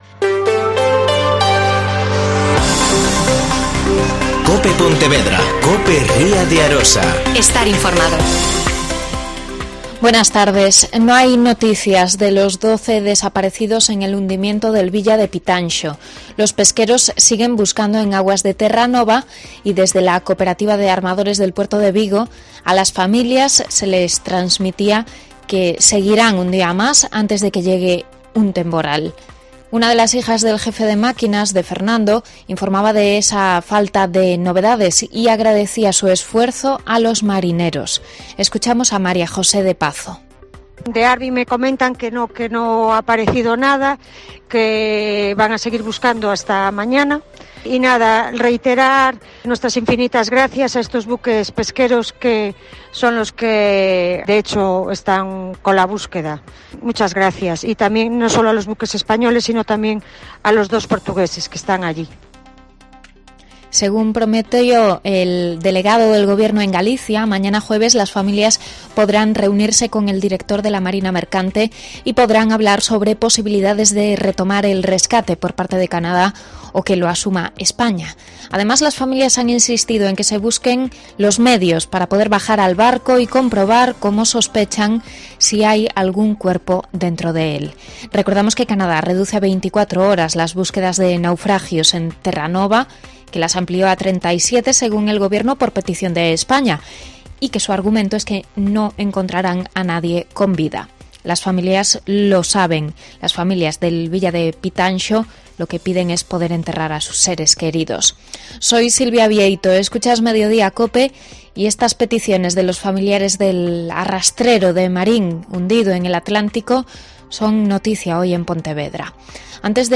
Mediodía COPE Pontevedra y COPE Ría de Arosa (Informativo 14:20h.)